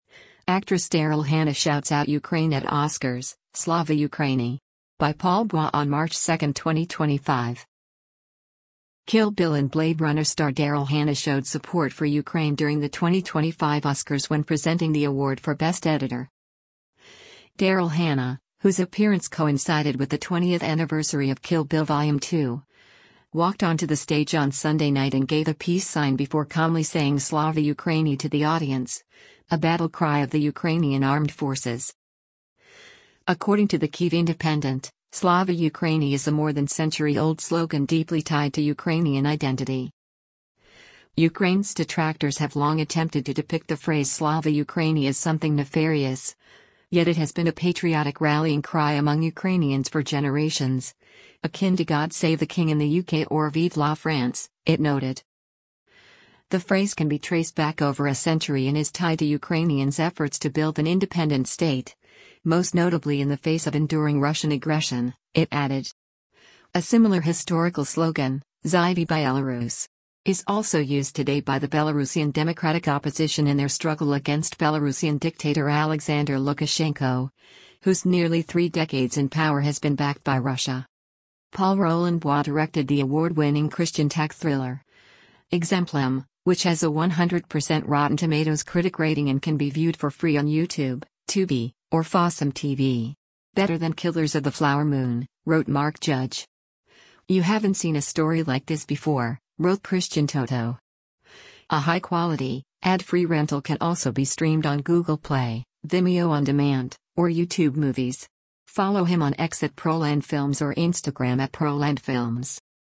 HOLLYWOOD, CALIFORNIA - MARCH 02: Daryl Hannah speaks onstage during the 97th Annual Oscar
Daryl Hannah, whose appearance coincided with the 20th anniversary of Kill Bill Vol. 2, walked onto the stage on Sunday night and gave a peace sign before calmly saying “Slava Ukraini” to the audience – a battle cry of the Ukrainian armed forces.